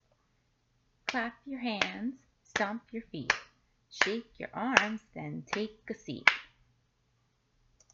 It allows you to change the way you tap each beat; you can clap your hands, pat your head, stomp your feet.